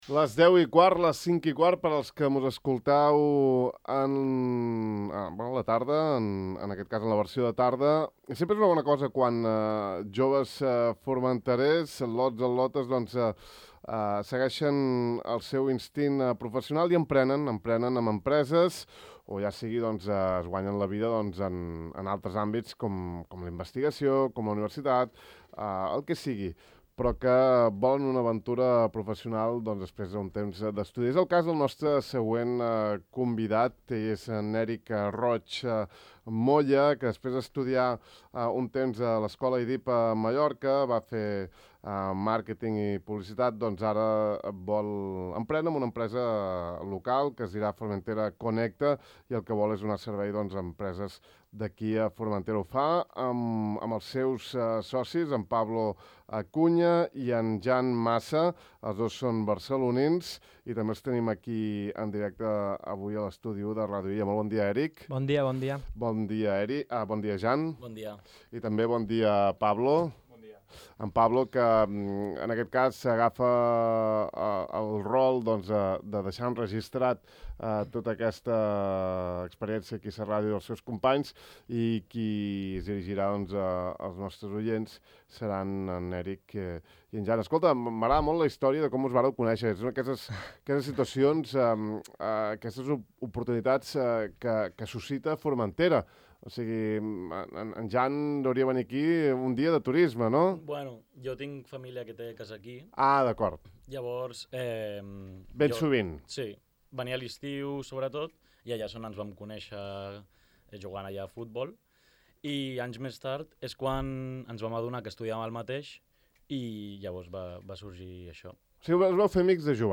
Podeu escoltar l’entrevista que els hi hem fet al De far a far d’avui aquí: